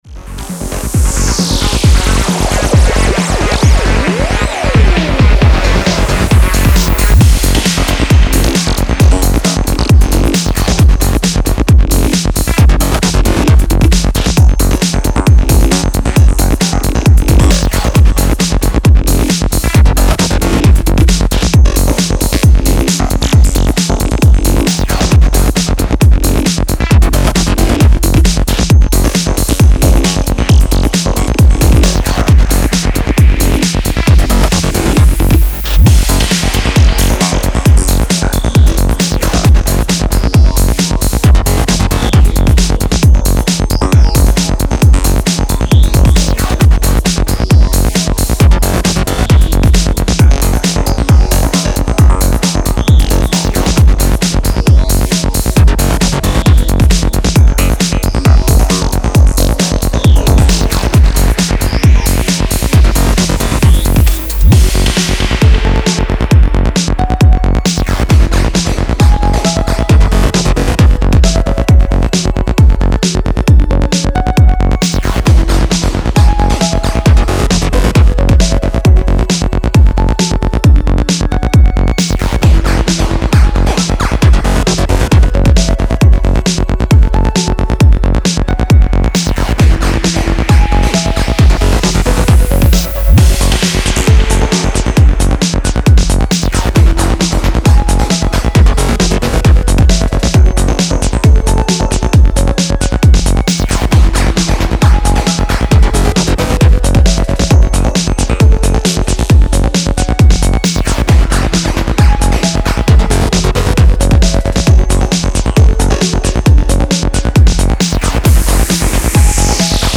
Breaks, Psy-Breaks, Breaks House, Funky Breaks